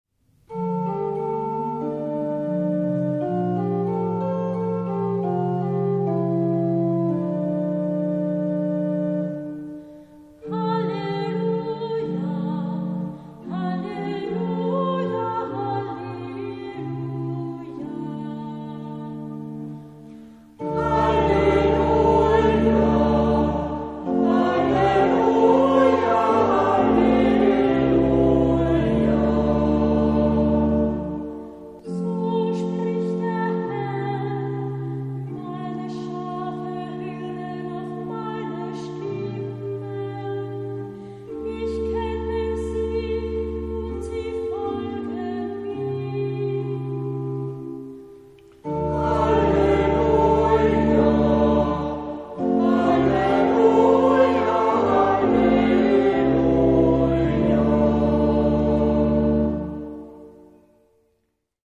Ruf vor dem Evangelium
Nr. 84/11 Gesang
Orgel